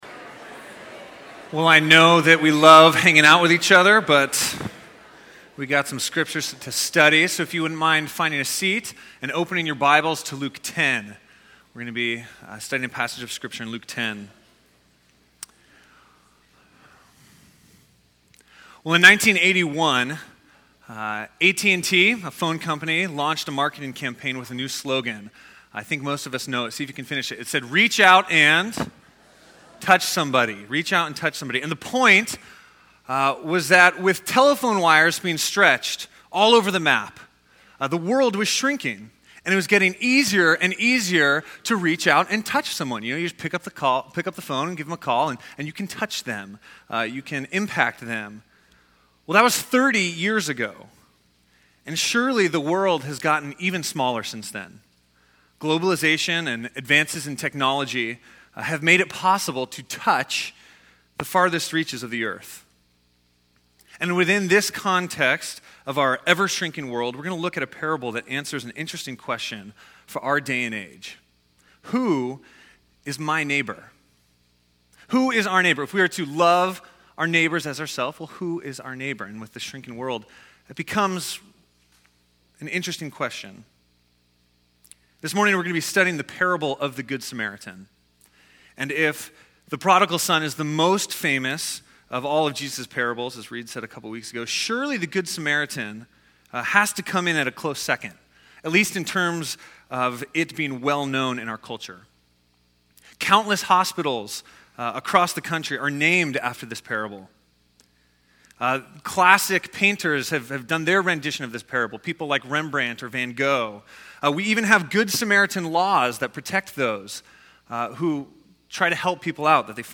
The Good Samaritan – 11am Service